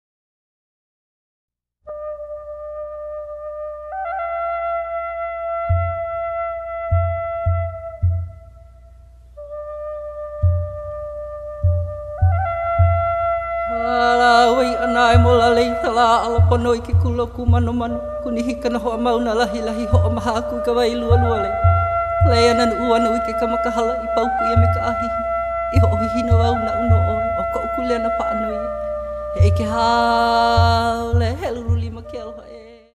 • Genre: Reissue; traditional Hawaiian.
a tenor) explores his upper-register falsetto tones
with effortless simplicity and grace.